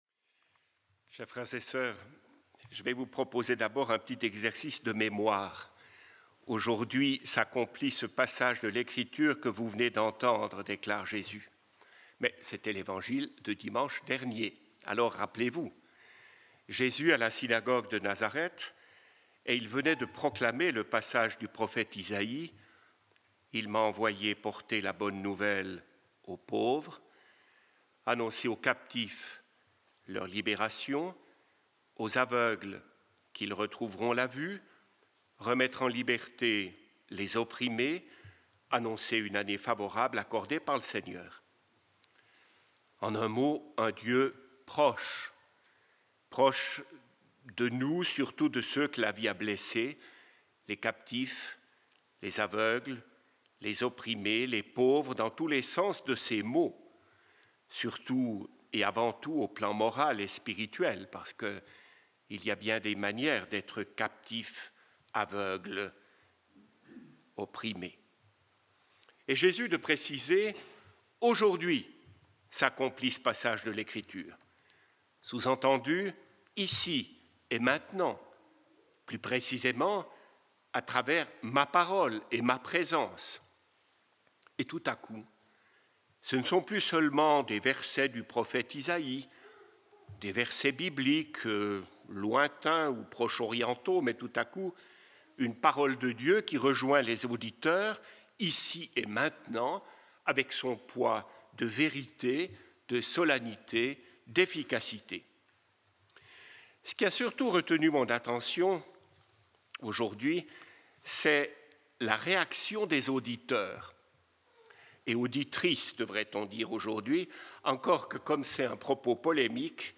Pour ce 4ème dimanche du Temps Ordinaire